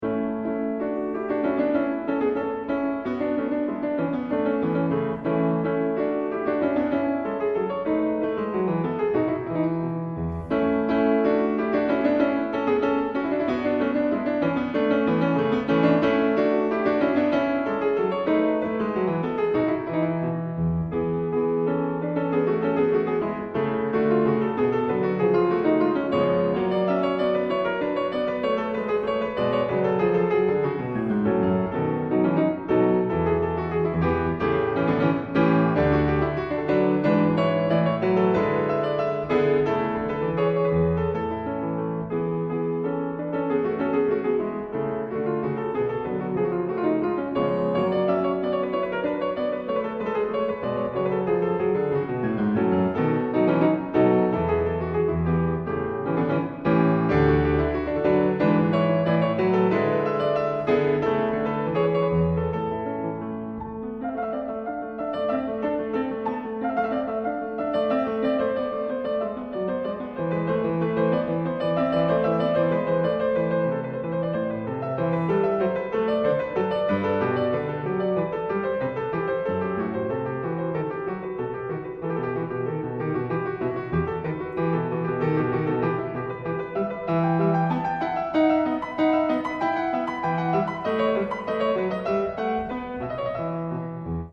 Grand Piano